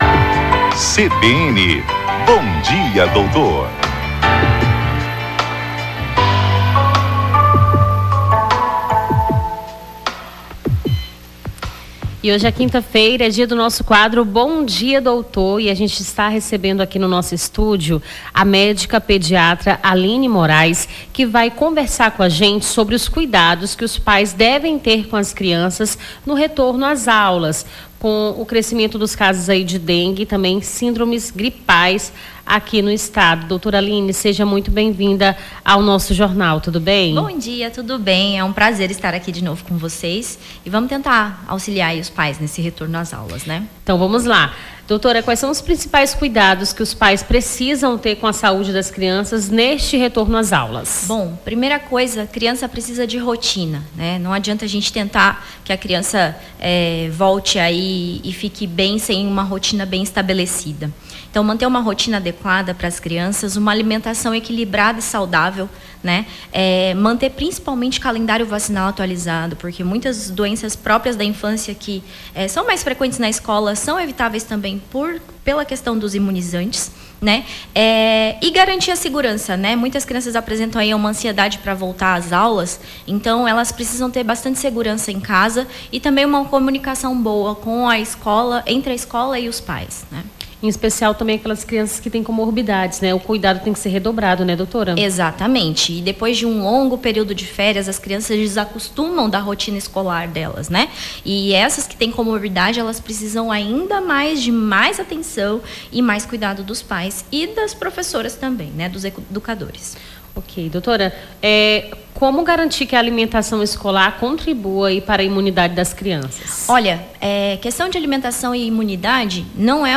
a apresentadora
conversou com a médica pediatra